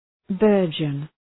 {‘bɜ:rdʒən}
burgeon.mp3